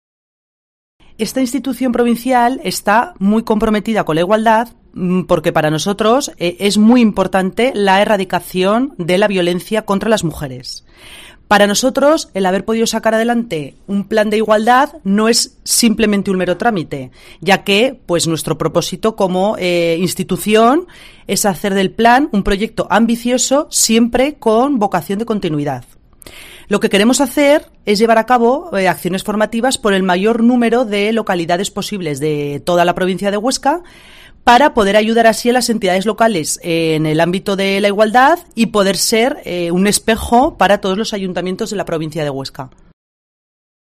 La diputada Lola Ibort explica el propósito del plan de Igualdad de la DPH